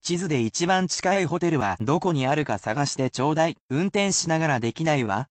Though you should certainly repeat after the words to assist you in learning them,the sentences are at normal speed, so you probably would benefit most from using these as listening practice.